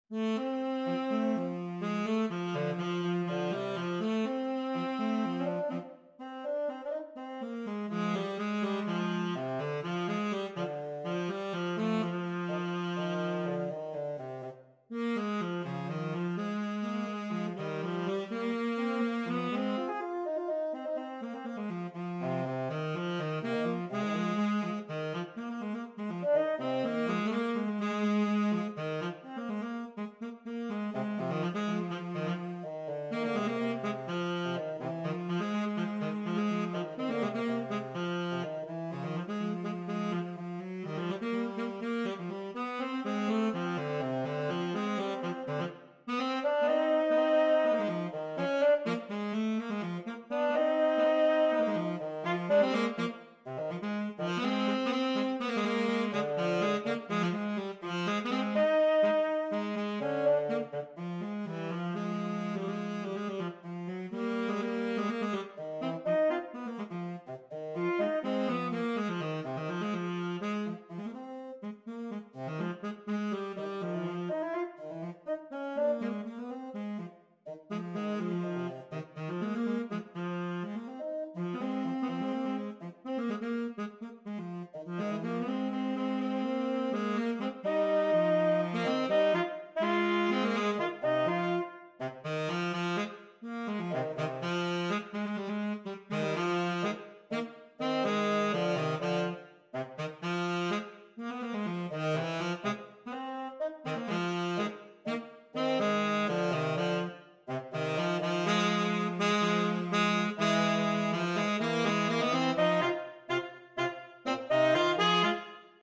Gattung: Für 2 Tenorsaxophone
Jazz-Duetten